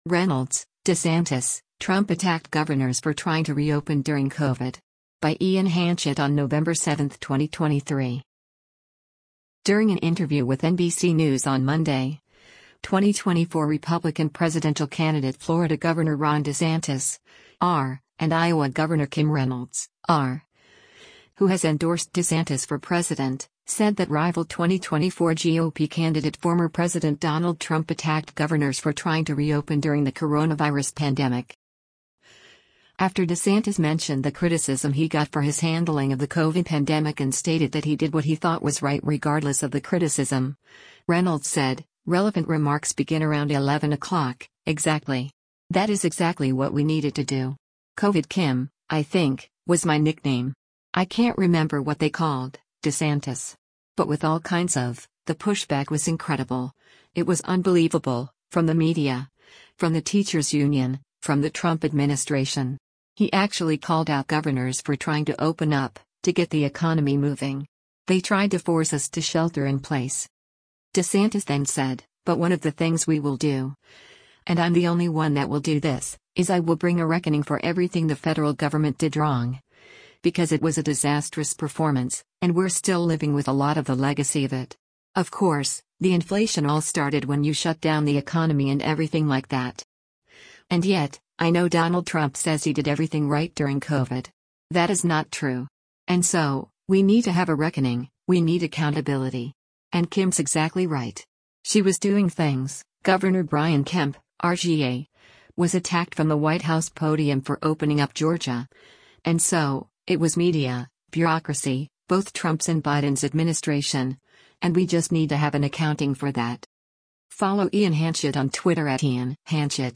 During an interview with NBC News on Monday, 2024 Republican presidential candidate Florida Gov. Ron DeSantis (R) and Iowa Gov. Kim Reynolds (R), who has endorsed DeSantis for president, said that rival 2024 GOP candidate former President Donald Trump attacked governors for trying to reopen during the coronavirus pandemic.